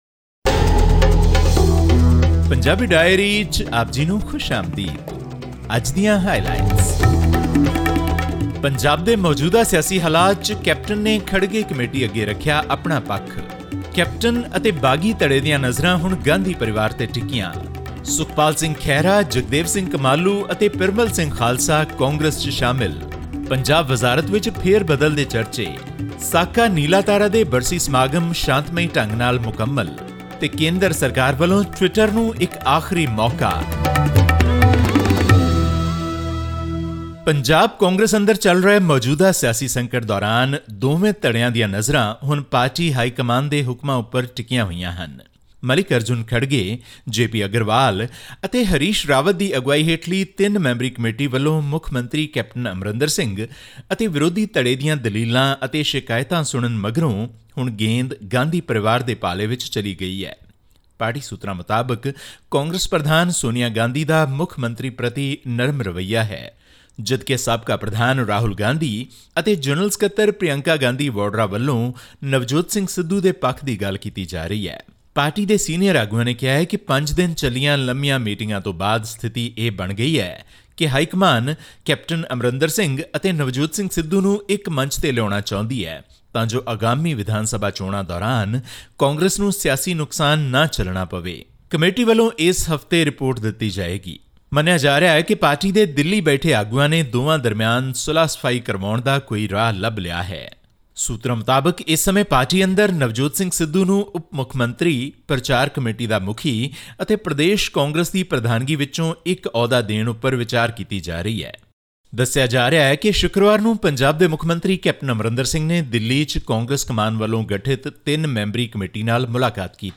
This and much more in our weekly news bulletin from Punjab.